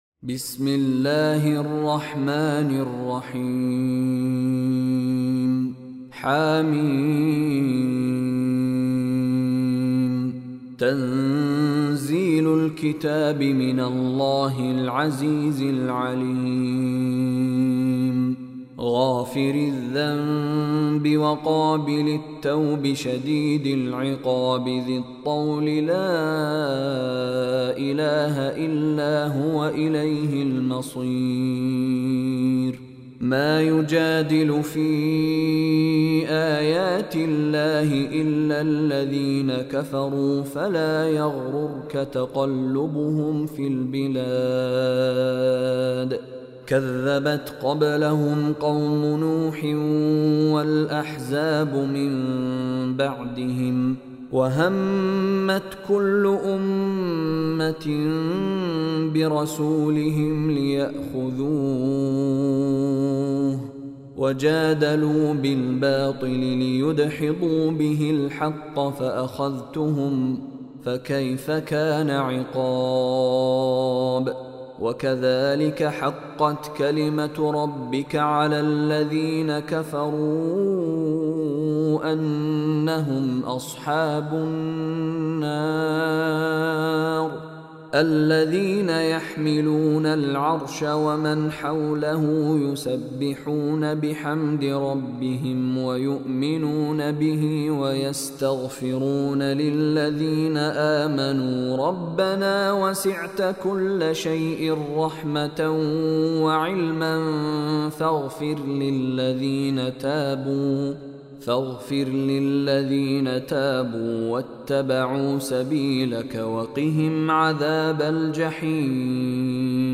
Surah Ghafir Online Recitation by Mishary Rashid
Surah Ghafir mp3 tilawat / recitation in the beautiful voice of Sheikh Mishary Rashid ALafasy.